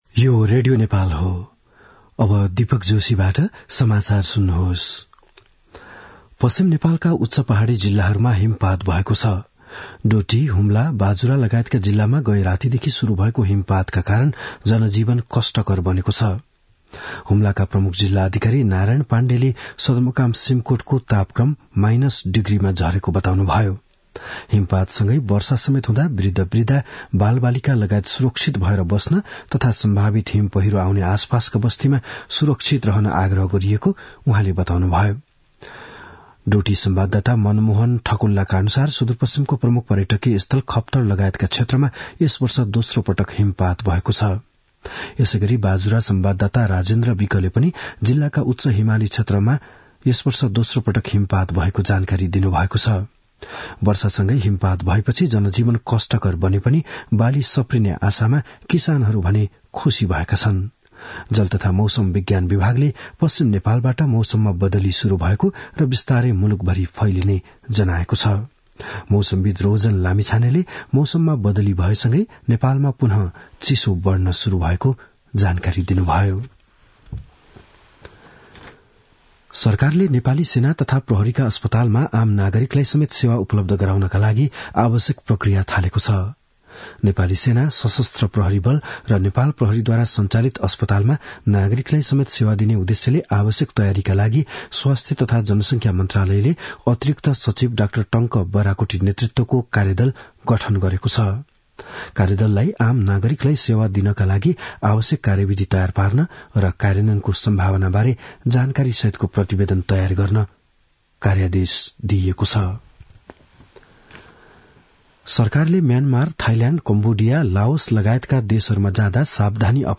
बिहान ११ बजेको नेपाली समाचार : १० पुष , २०८१
11-am-nepali-news-1-19.mp3